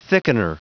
Prononciation du mot thickener en anglais (fichier audio)
Prononciation du mot : thickener